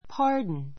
pardon 中 A1 pɑ́ː r dn パ ー ド ン 名詞 許し beg [ask for] pardon beg [ ask for ] pardon 許しを願う I beg your pardon.